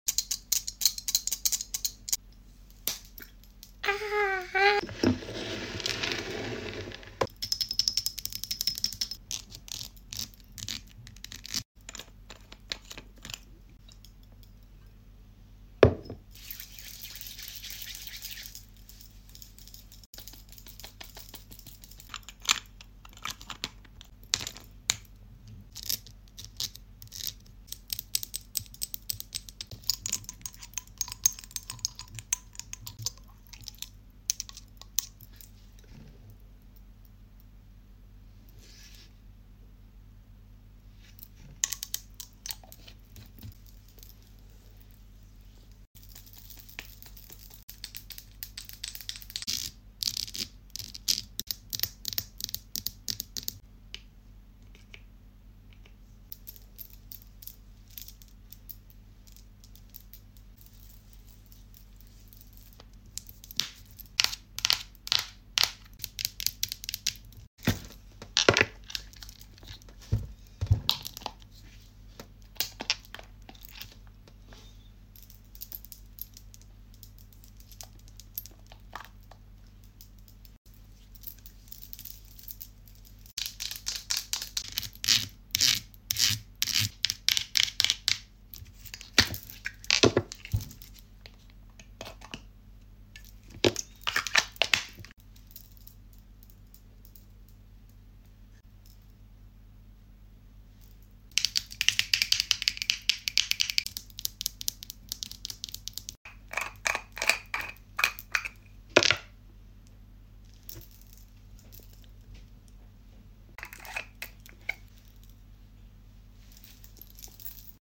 Get unready with me ASMR💕 sound effects free download